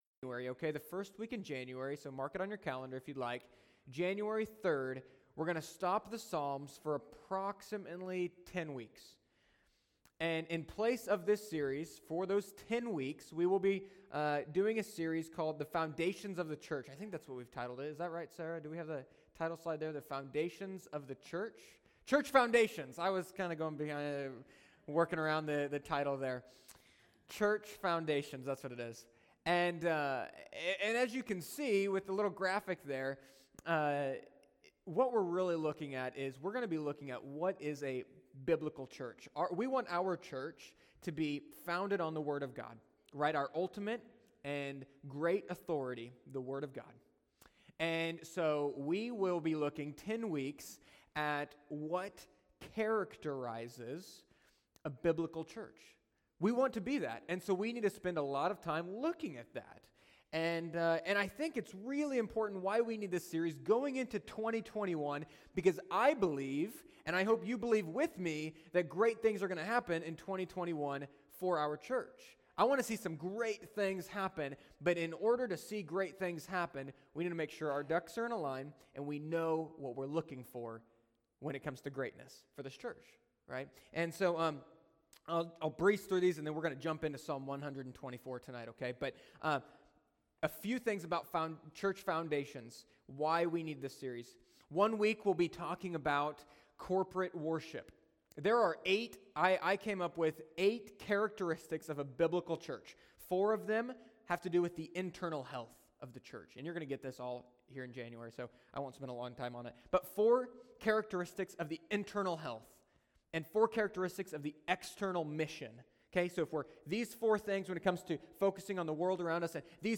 Sermons Archive – Page 2 of 143 – Immanuel Baptist Church
Sunday-Evening-11-29.mp3